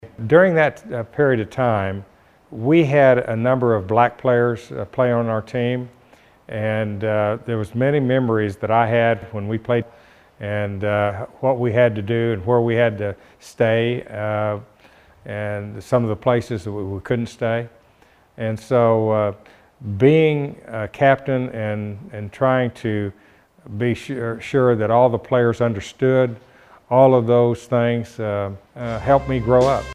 Ketchum talked about some of his memories as captain of the KU men’s basketball team in 1961 during his Bartlesville Sports Hall of Fame ceremony.